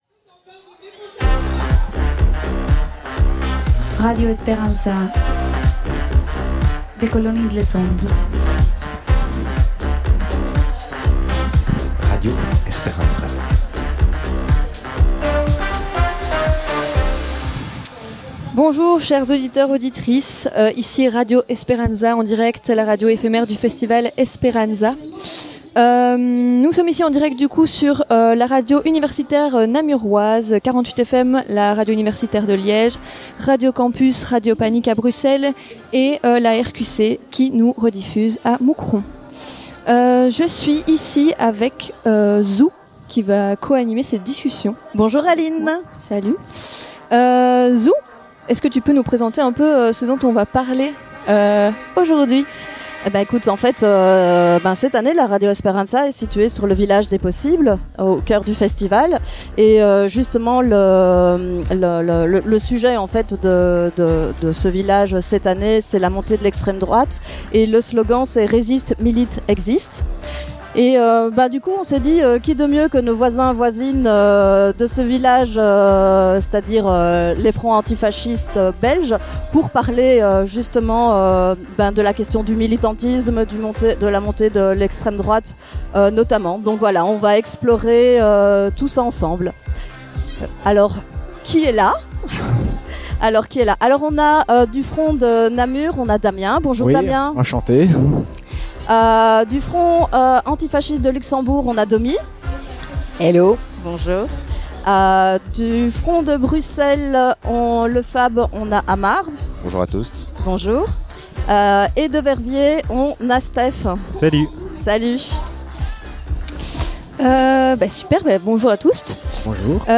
Interview des différents fronts présents sur dans le Village des Possibles